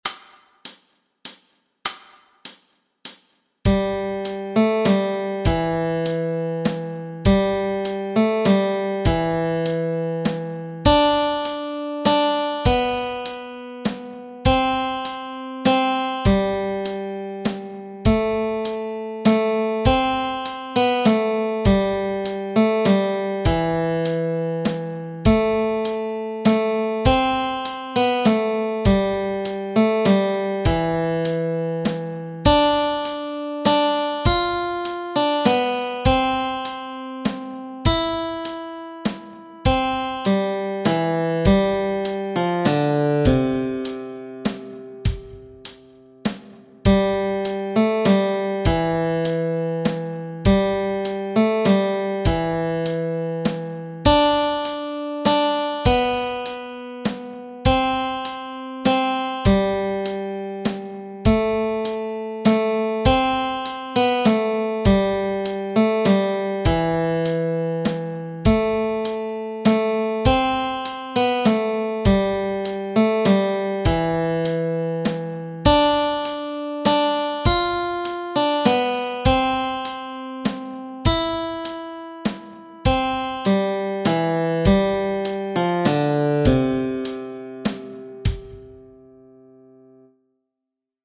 Melody Track
Silent-Night-melody.mp3